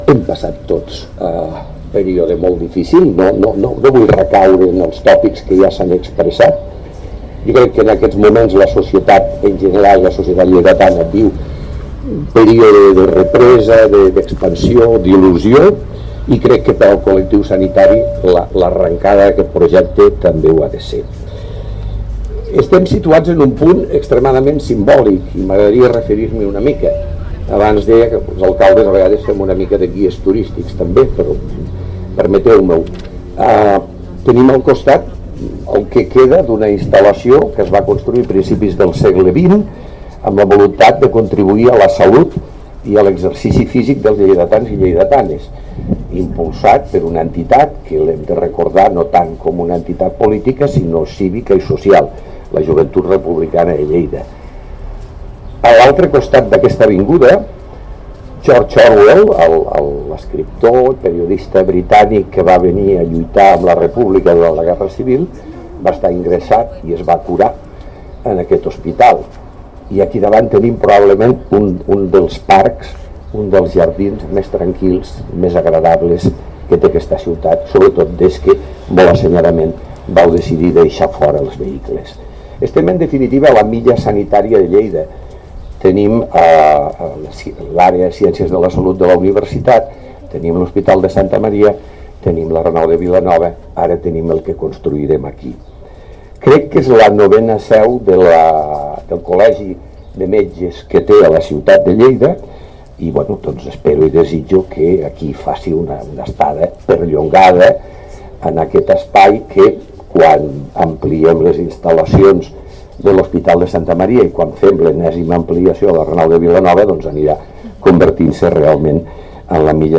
tall-de-veu-de-lalcalde-miquel-pueyo-sobre-la-col-locacio-de-la-1a-pedra-de-la-nova-seu-del-col-legi-oficial-de-metges